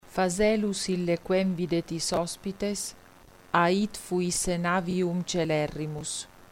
trimetro.mp3